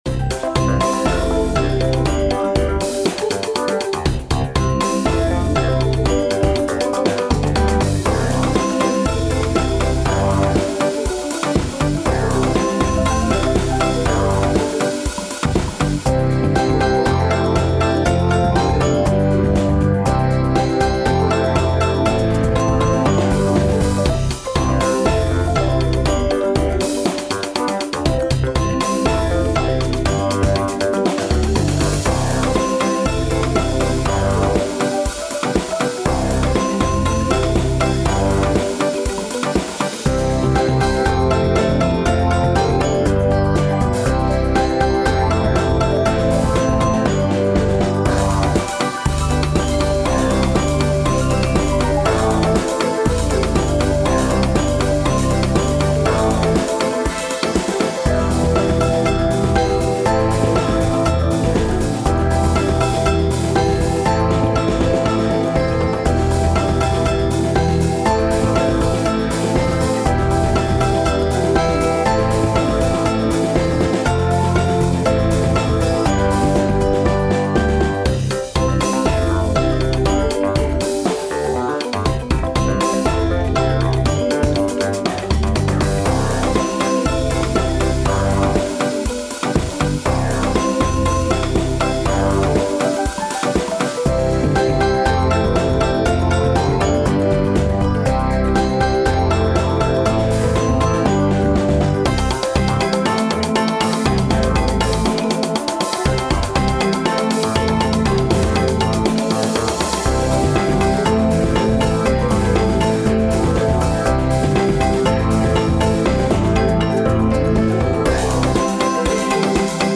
midi-demo 1